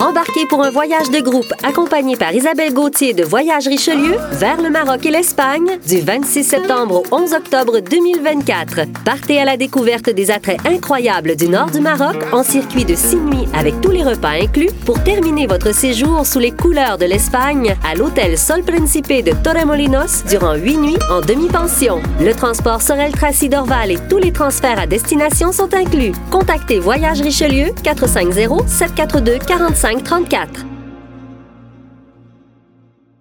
PUBlicité – ton souriant